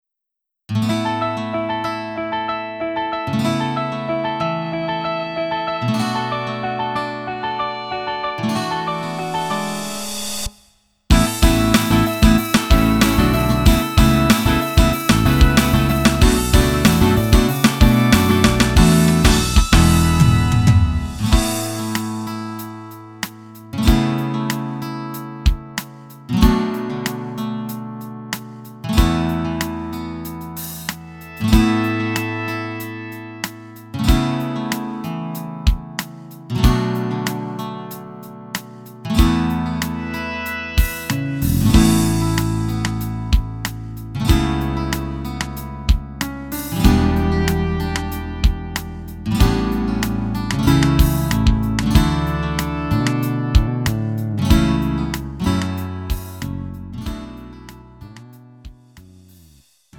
음정 원키
장르 구분 Lite MR